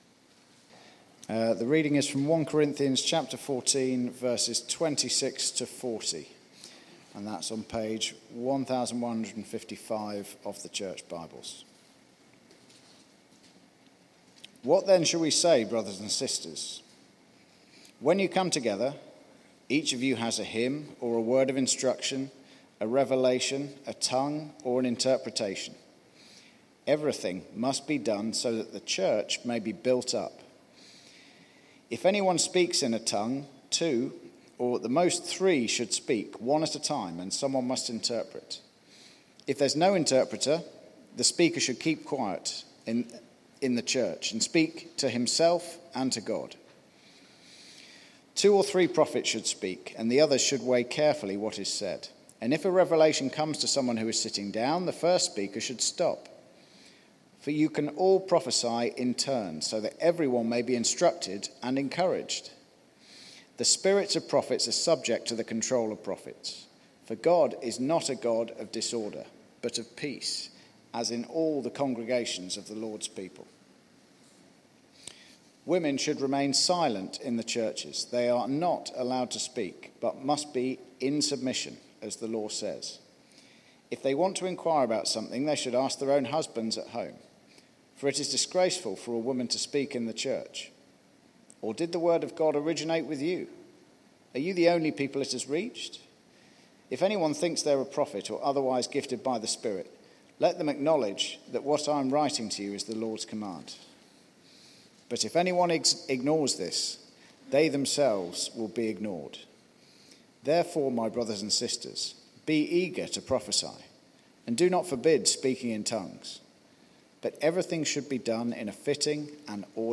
Media for Church at the Green Sunday 4pm
Theme: Sermon